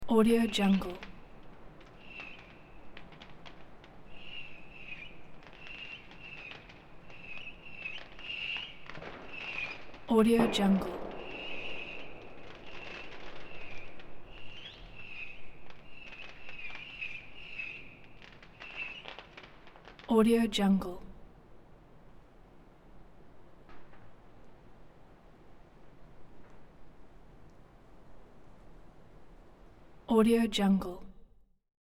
دانلود افکت صوتی شهری
• ایجاد جو جشن و هیجان: صدای انفجار آتش‌بازی، سوت کشیدن فشفشه‌ها و هیاهوی جمعیت، حس شادی و هیجان را به بیننده منتقل می‌کند و می‌تواند برای ایجاد جوهای جشن، سال نو، رویدادهای خاص یا صحنه‌های هیجان‌انگیز در ویدیوهای شما استفاده شود.
16-Bit Stereo, 44.1 kHz